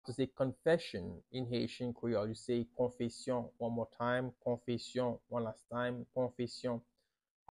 How to say “Confession” in Haitian Creole – “Konfesyon” pronunciation by native Haitian teacher
“Konfesyon” Pronunciation in Haitian Creole by a native Haitian can be heard in the audio here or in the video below: